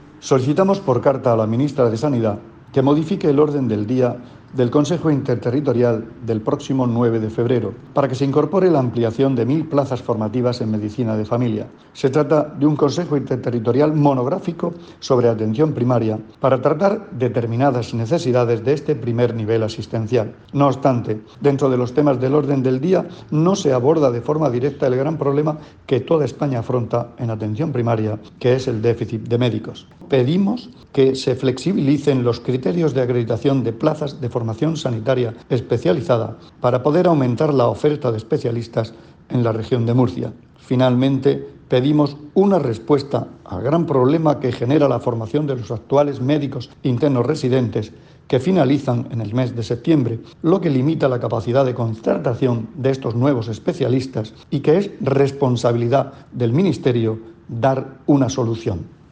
Declaraciones del consejero de Salud, Juan José Pedreño, sobre las peticiones que ha remitido a la ministra de Sanidad.